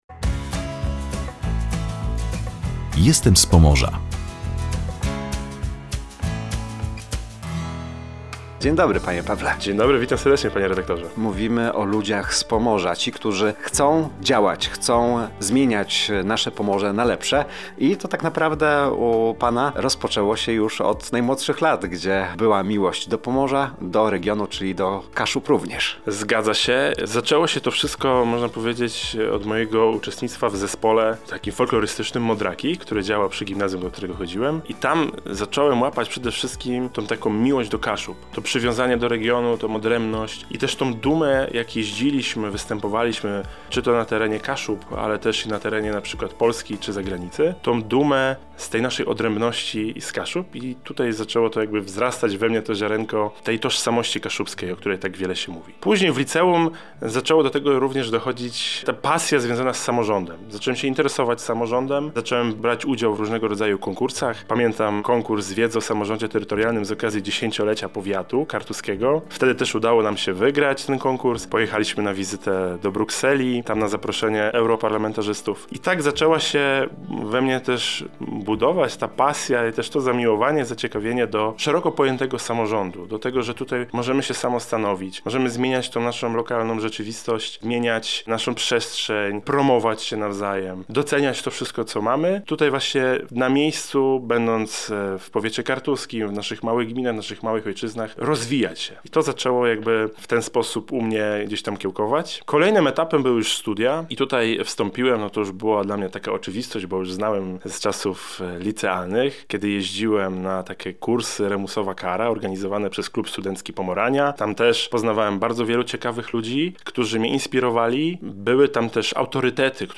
Gościem audycji „Jestem z Pomorza” był Paweł Kowalewski, członek zarządu powiatu kartuskiego, działacz regionalny i społeczny. Od zawsze związany z samorządem.